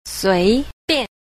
4. 隨便 – suíbiàn – tùy tiện